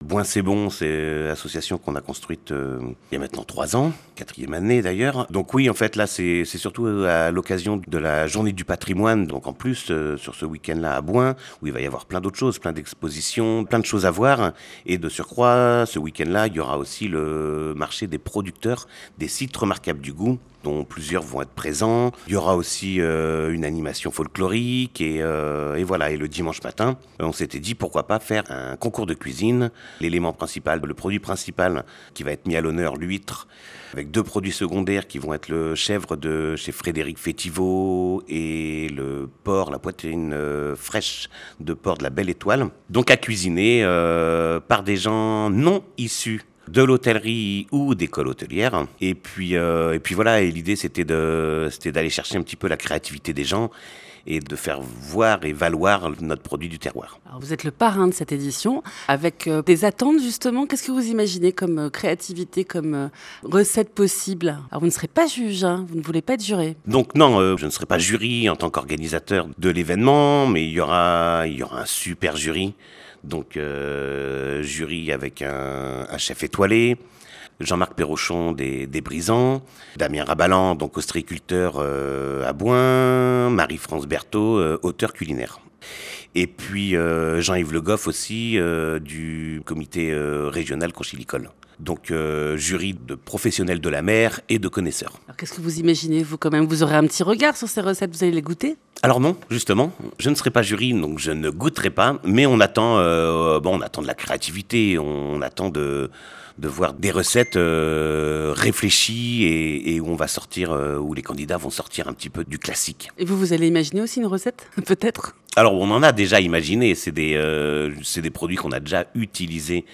Les Reportages de NOV FM